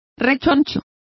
Complete with pronunciation of the translation of chubbiest.